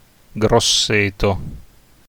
Grosseto (Italian pronunciation: [ɡrosˈseːto]
It-Grosseto.ogg.mp3